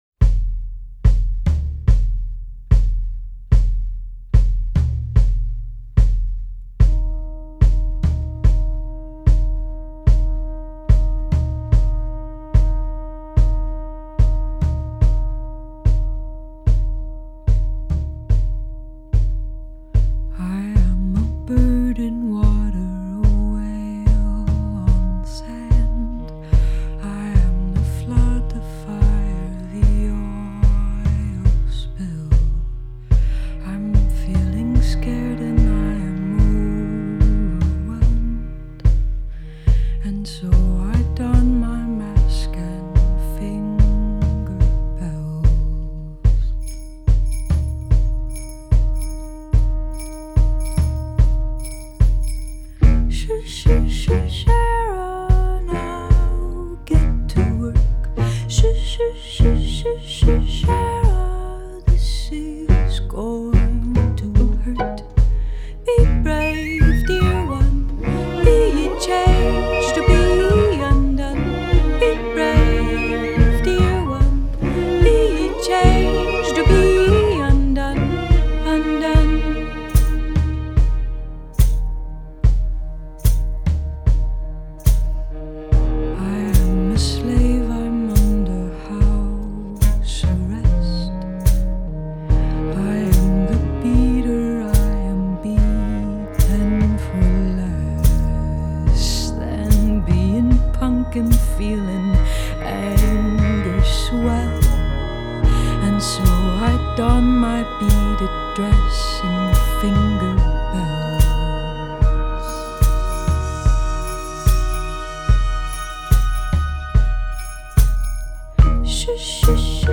NYC-based ensemble
to create a lush backdrop for her gorgeous voice